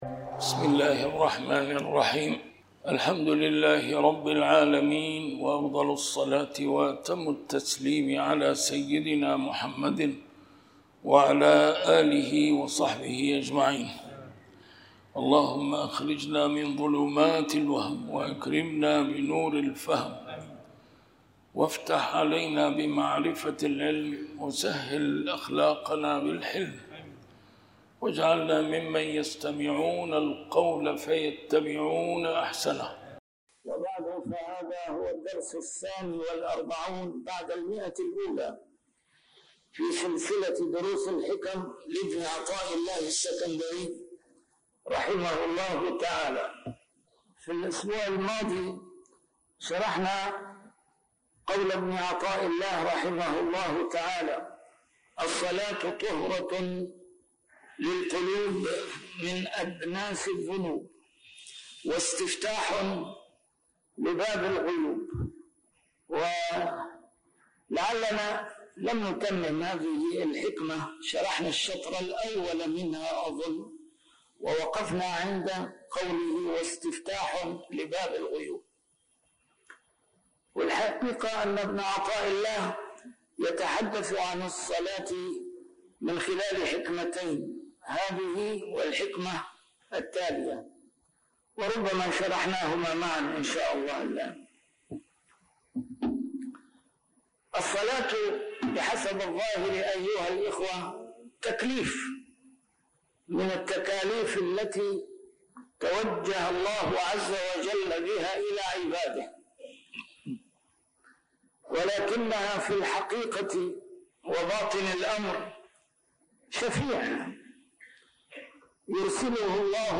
A MARTYR SCHOLAR: IMAM MUHAMMAD SAEED RAMADAN AL-BOUTI - الدروس العلمية - شرح الحكم العطائية - الدرس رقم 142 شرح الحكمة 119+120